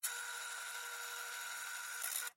Звуки бинокля
Звук увеличения в ночном бинокле